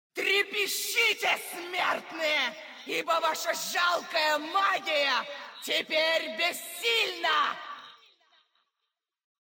Когда босс применяет какую-нибудь свою способность он издает при этом определенные звуки или говорит «дежурные» фразы, порой весьма раздражающие… smile Рассмотрим это на примере босса Цитадели Ледяной Короны – Синдрагосы.
Использование Синдрагосой способности «Освобожденная магия»: